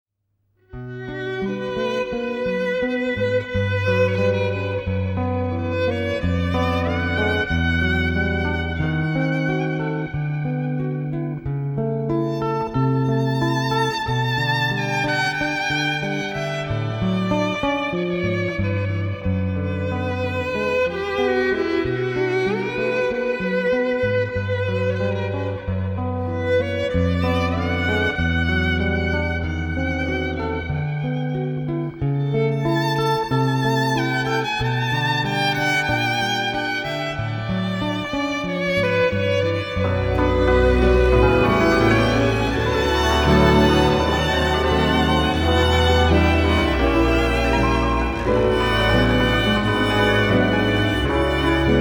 soloist violin
Cymbalom in Gypsy Orchestra
Doublebass
Guitar in Gypsy Orchestra
Clarinet in Gypsy Orchestra
Second Violin in Gypsy Orchestra
Piano in Gypsy Orchestra